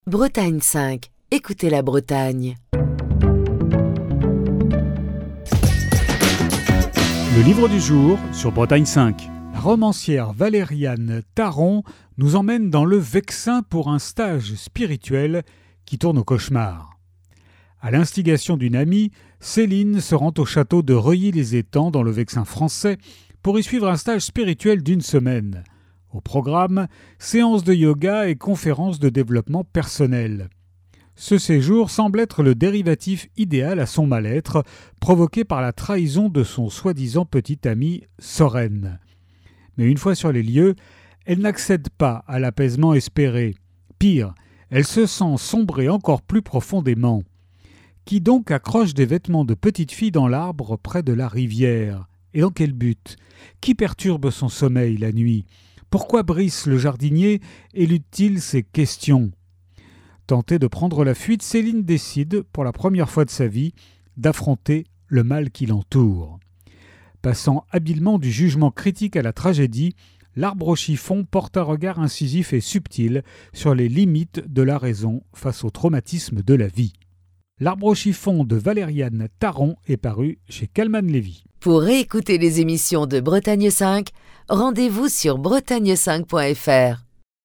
Chronique du 24 octobre 2024.